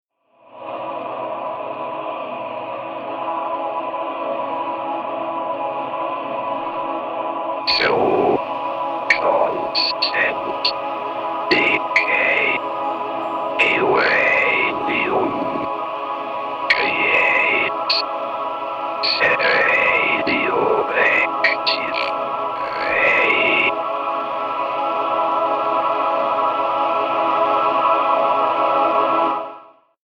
Comparé à l’Optigan, l’Orchestron utilise le même principe de lecture optique de disque en celluloid mais sa conception est plus robuste (utilisation de bois au lieu de plastique) et plus fiable. La qualité de reproduction sonore est donc tout aussi perfectible et loin de rivaliser avec son concurrent direct, le Mellotron.